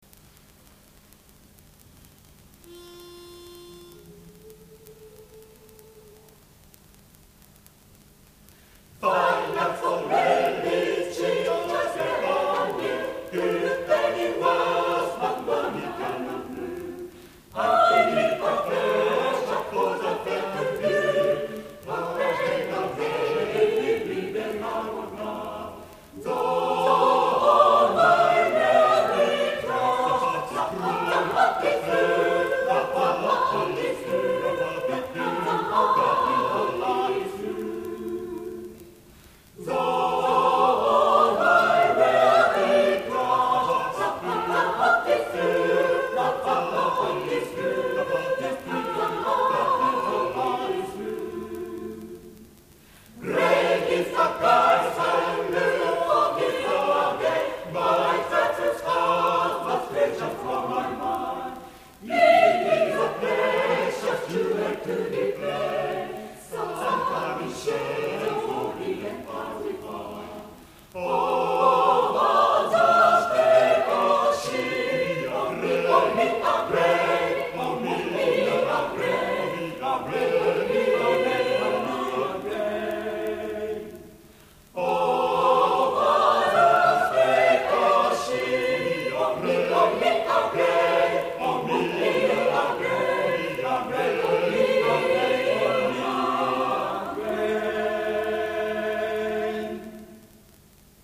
第34回野田市合唱祭
野田市文化会館